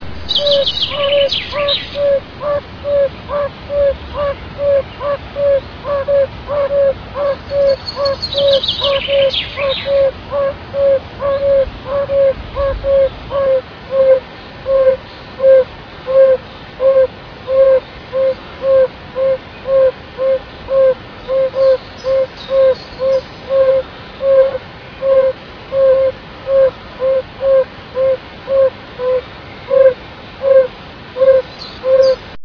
Bombina variegata variegata
bombina.rm